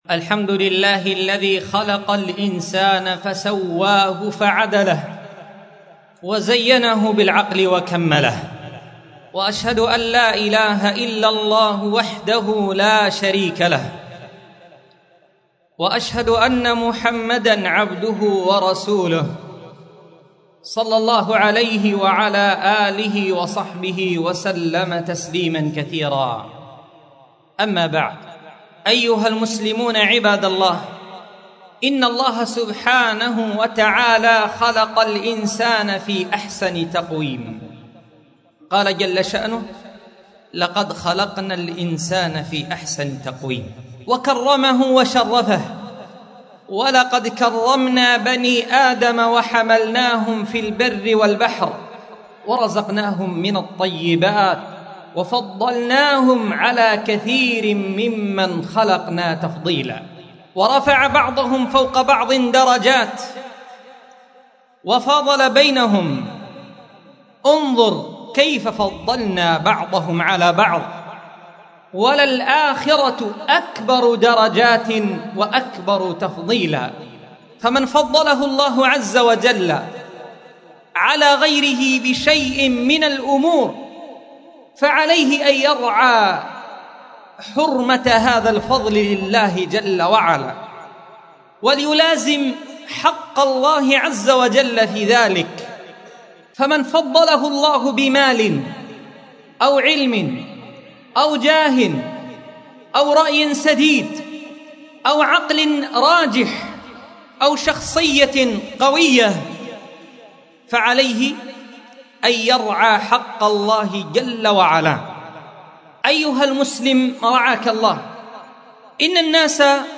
مسجد درة عدن محافظة عدن حرسها الله / 1447 / 14 / جمادى الثانية